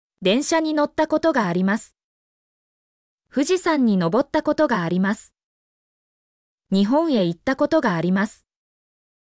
mp3-output-ttsfreedotcom-47_HXj5yFDE.mp3